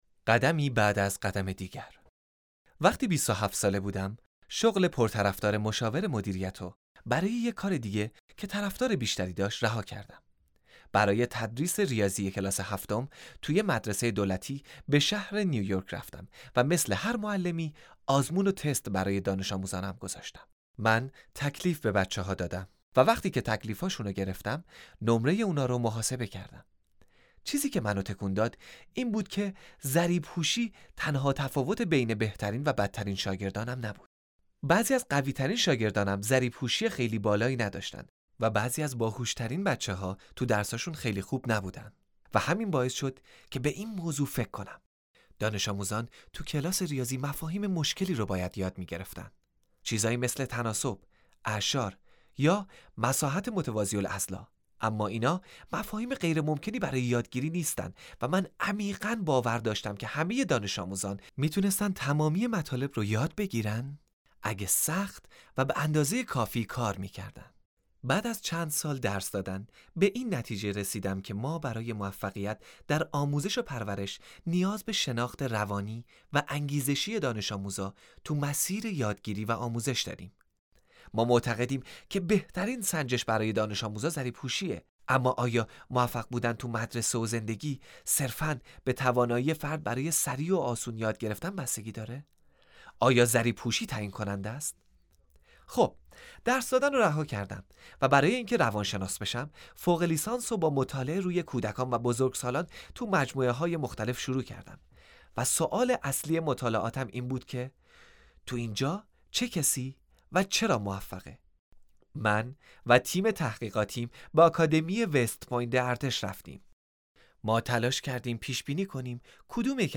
این پادکست از زبان سخنران صحبت میکنه و داستان زندگی اش رو بیان میکنه.